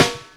Snare Drum 67-06.wav